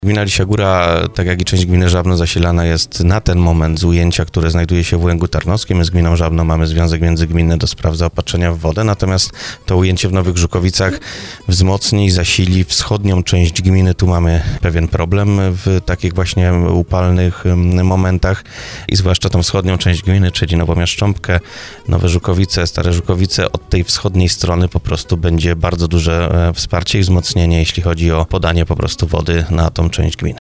Wójt Arkadiusz Mikuła, który był gościem porannej rozmowy Słowo za Słowo przyznaje, że nowe ujęcie wody jest gminie bardzo potrzebne.